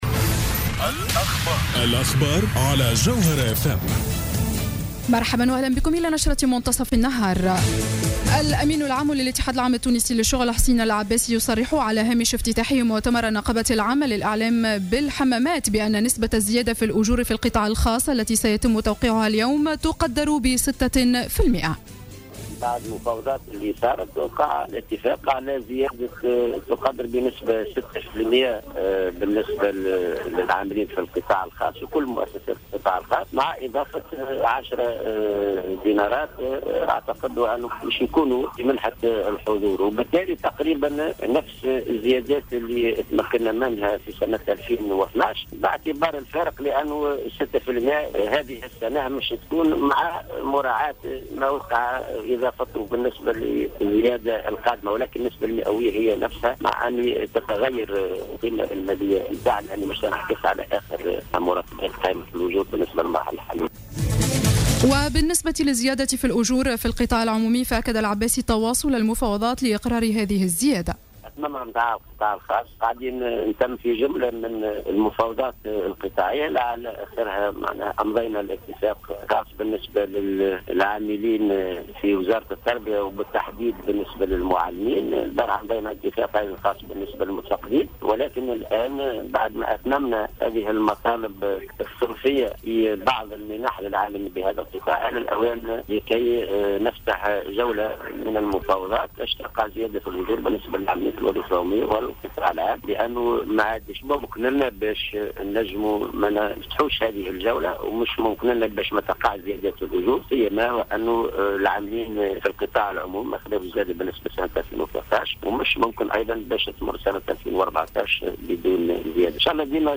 نشرة أخبار منتصف النهار ليوم الاثنين 23-06-14